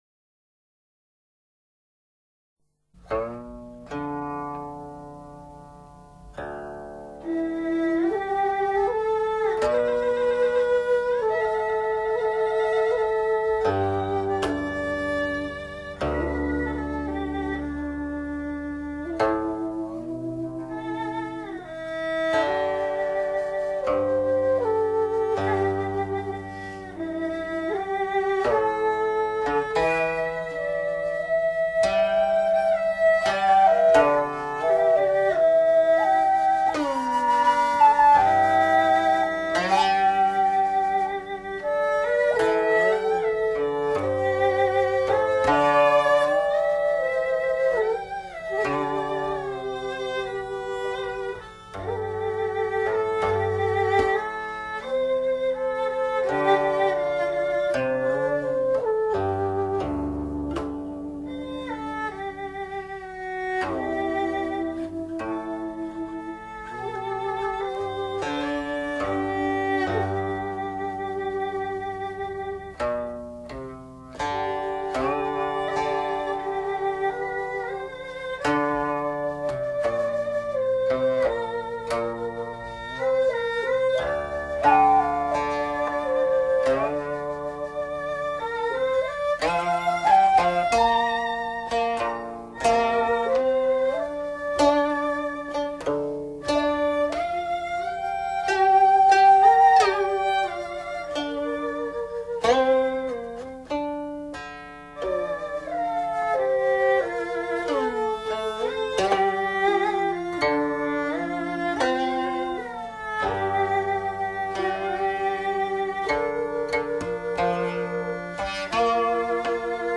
古琴、箫、二胡合奏
古琴.箫.二胡